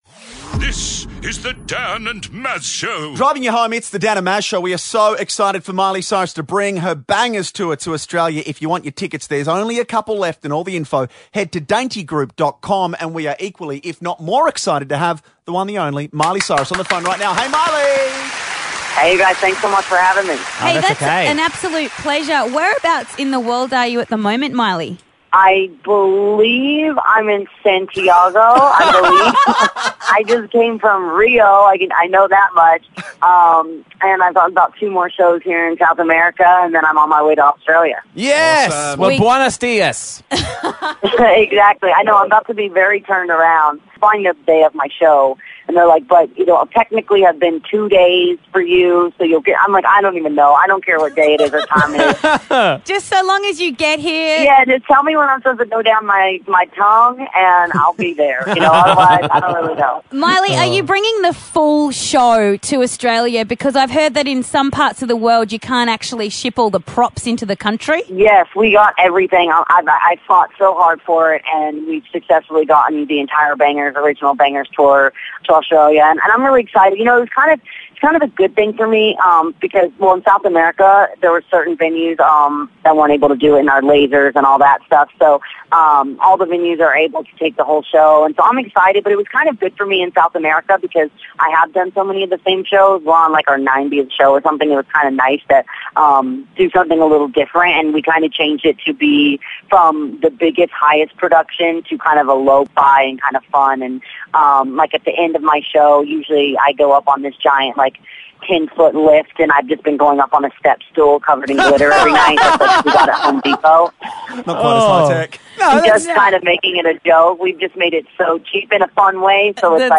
Superstar Miley Cyrus calls in from Brazil!